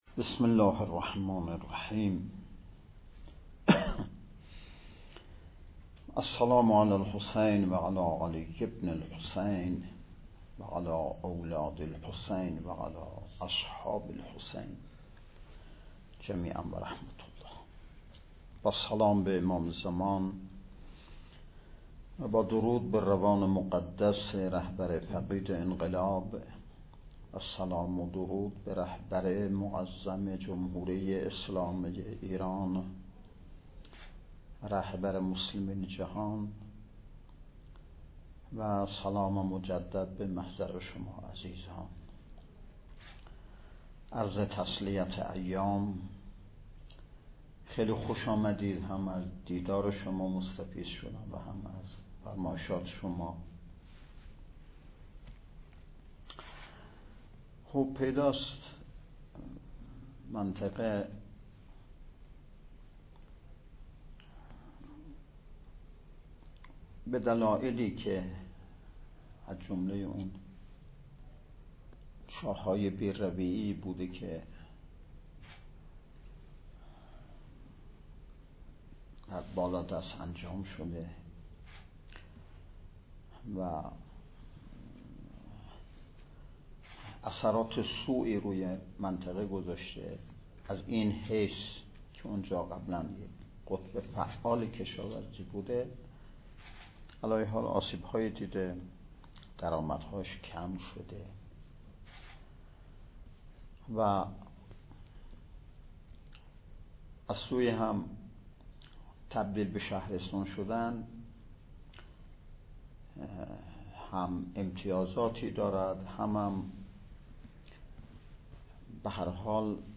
صوت / بیانات نماینده ولی فقیه در خراسان جنوبی در دیدار با اعضای شورای اسلامی شهر خوسف
بیانات-در-دیدار-اعضای-شورای-اسلامی-شهر-خوسف.mp3